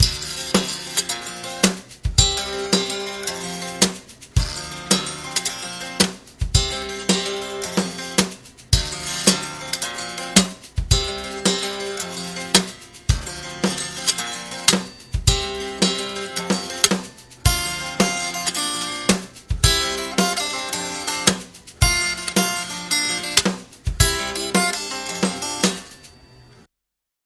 こちらは試しにテンポアップさせてBPM 110 に設定を行い
簡単なドラムフレーズを打ち込んだものとなります。
元の音源が携帯で録音したもののため高音質ではないものの、